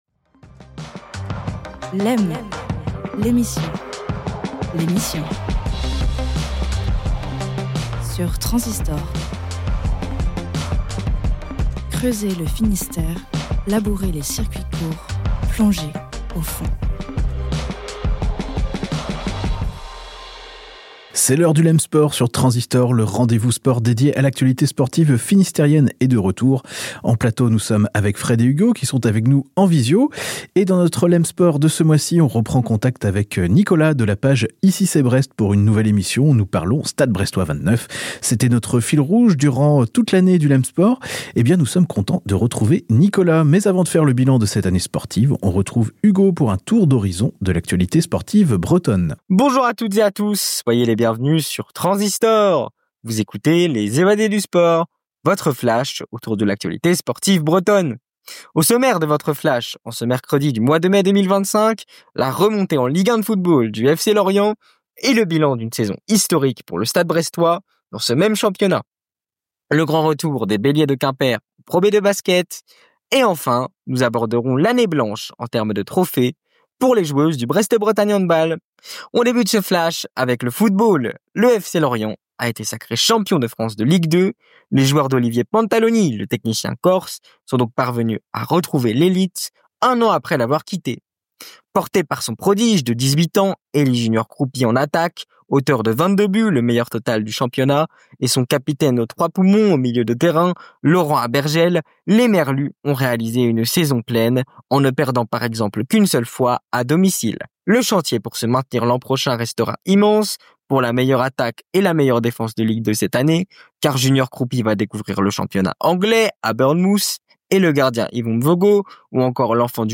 Une fois le flash breton terminé, on retrouve tout le monde en plateau pour aborder les sujets brûlants autour du Stade Brestois 29. Et on commence par une excellente nouvelle pour les supporters : la prolongation d’Éric Roy sur le banc brestois.